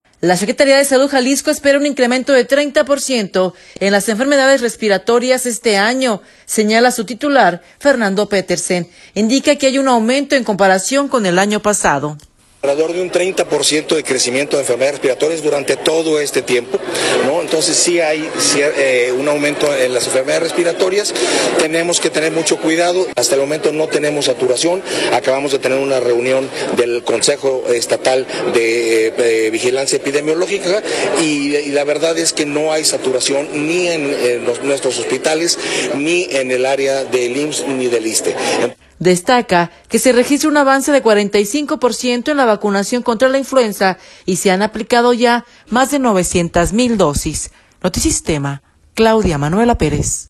La Secretaría de Salud Jalisco espera un incremento de 30 por ciento en las enfermedades respiratorias este año, señala su titular, Fernando Petersen. Indica que hay un aumento en comparación con el año pasado.